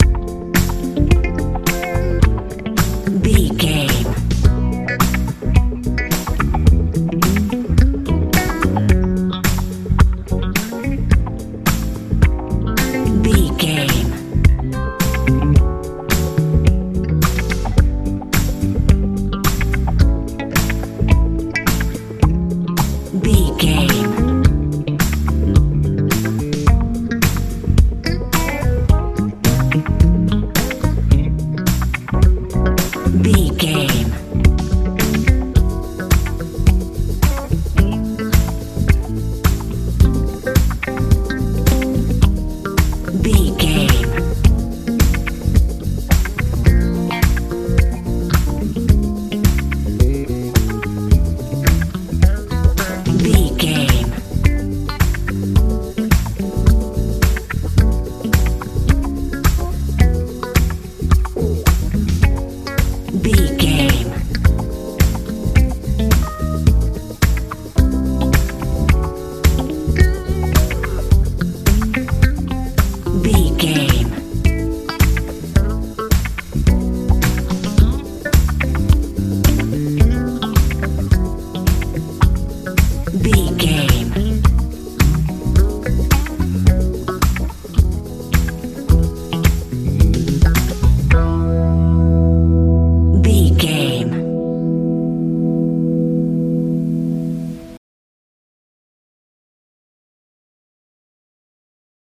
funk soul feel
Ionian/Major
groovy
funky
organ
electric guitar
bass guitar
drums
70s
80s